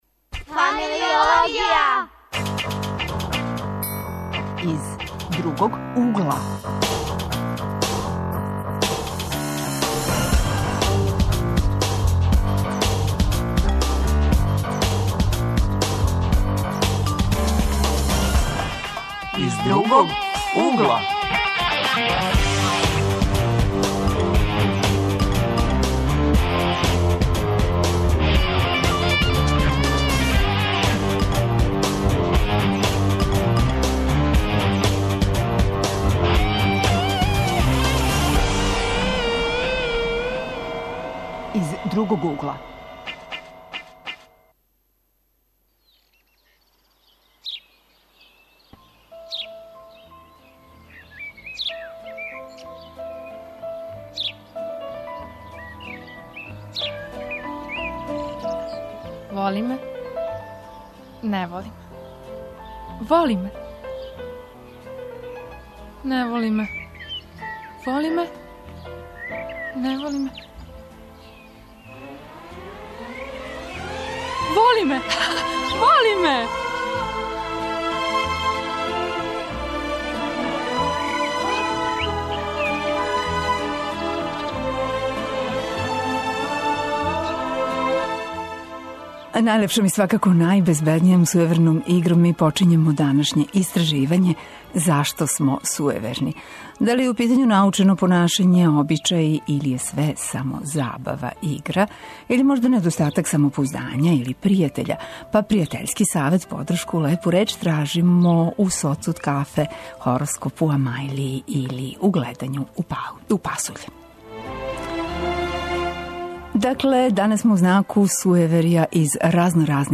Гости емисије су матуранти и студенти.